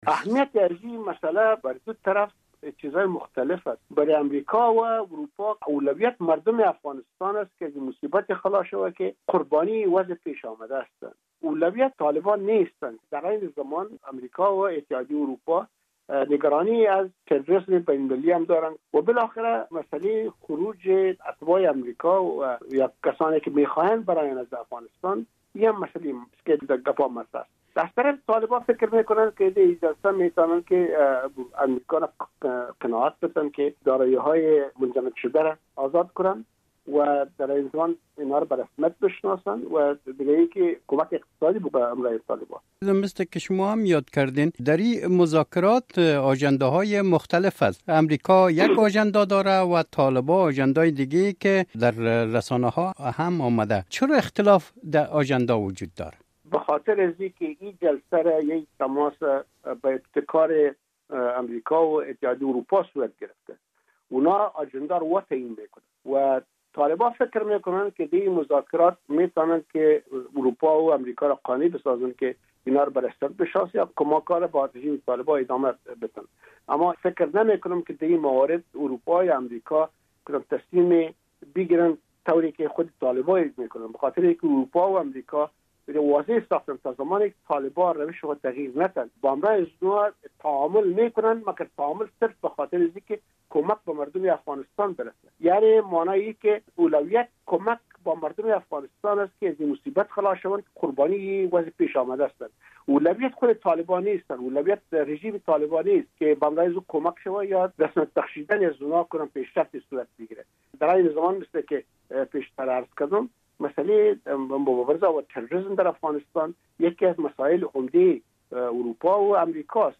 مصاحبه - صدا
در باره مذاکرات قطر مصاحبه را با علی احمد جلالی استاد در اکادمی نظامی امریکا در واشنگتن انجام دادام و نخست از وی پرسیدم که این نشست چه اهمیت دارد؟